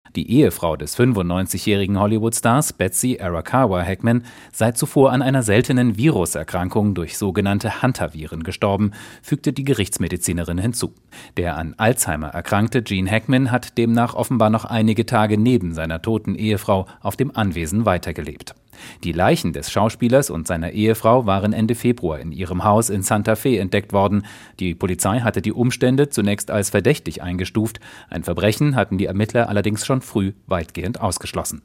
Sie sprechen von natürlichen Todesursachen. SWR3 Korrespondent